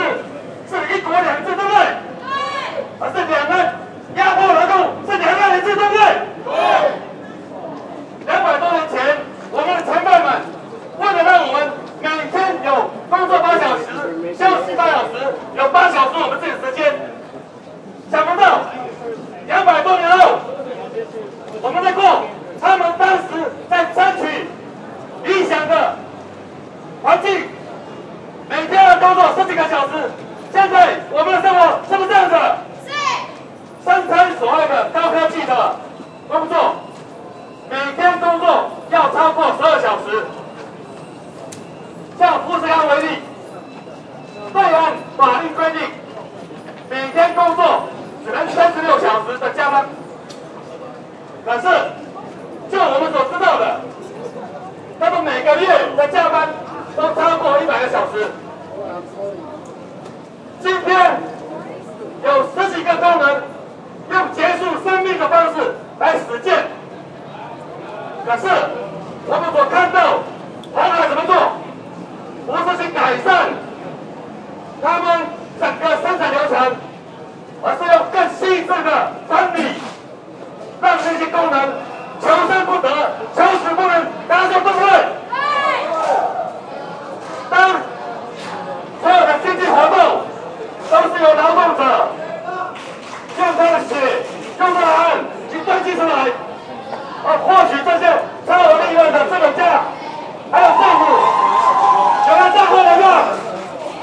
Protest demonstration about Foxconn poor worker conditions at Computex Taipei Taiwan